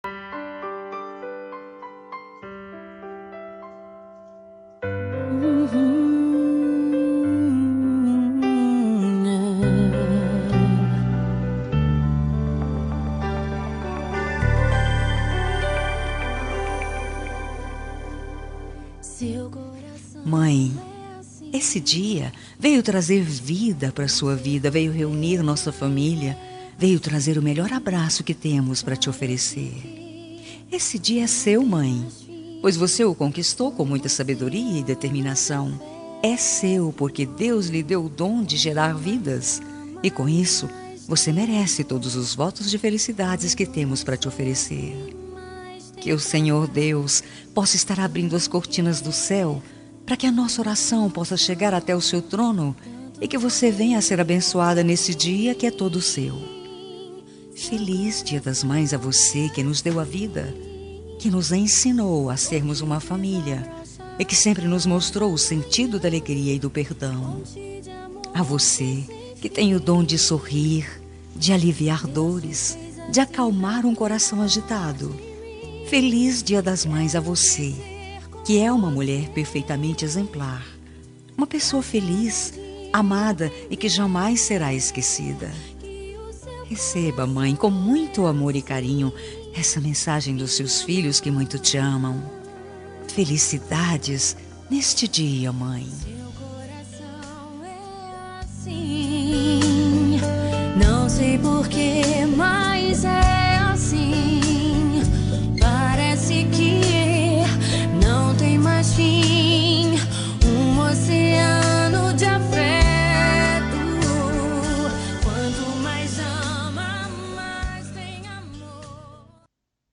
Dia das Mães – Para minha Mãe – Voz Feminina – Plural – Cód: 6508